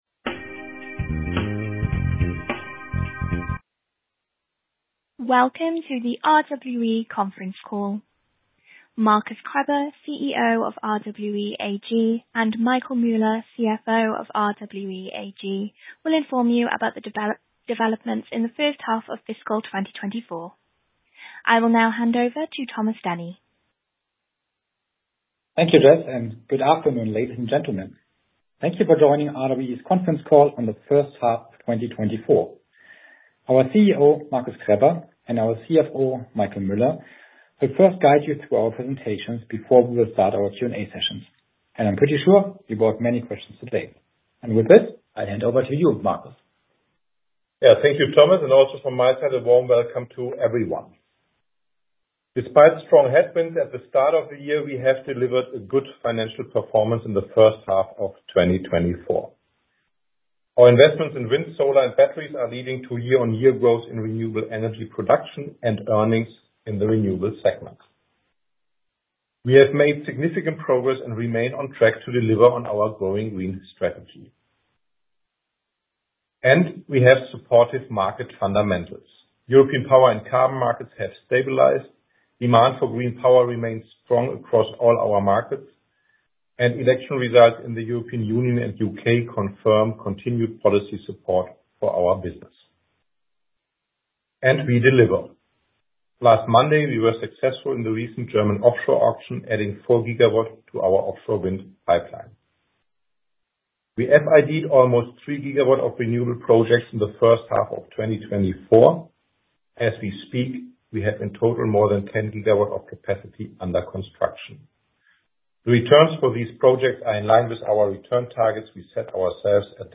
Investoren- und Analysten-Telefonkonferenz